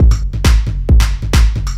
DS 135-BPM B5.wav